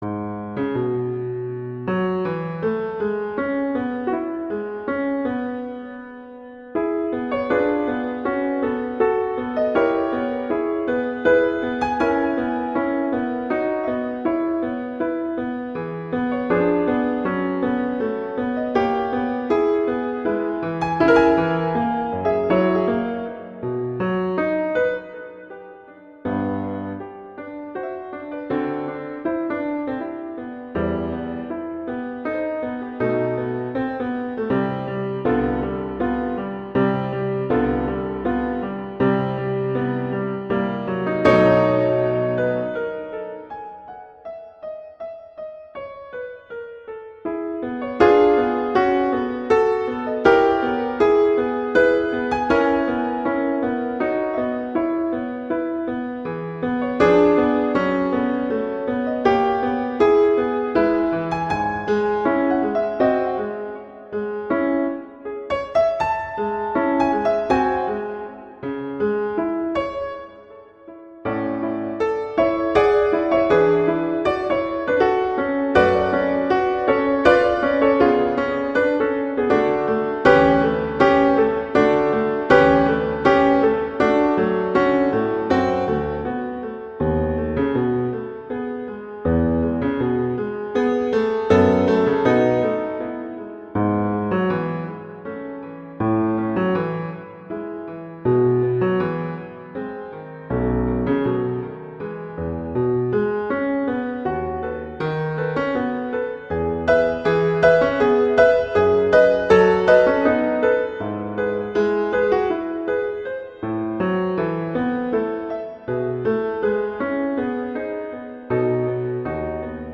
classical
Db major, E major